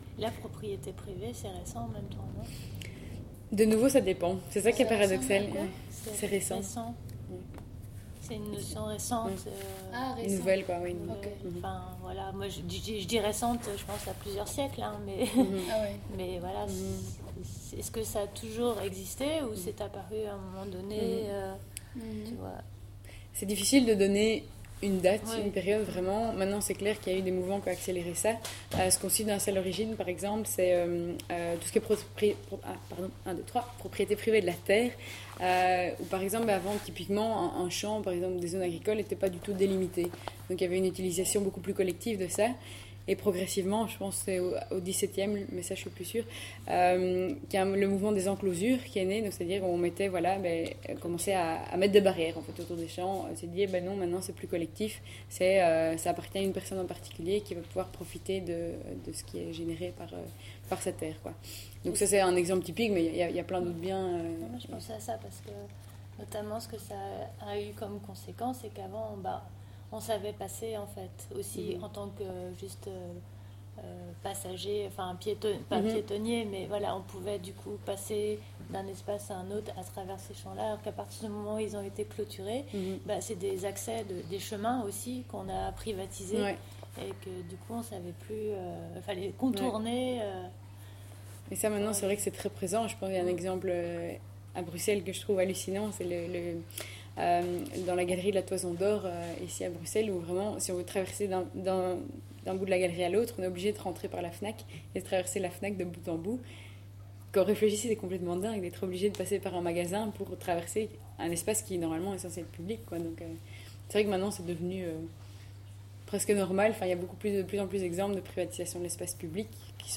commentaire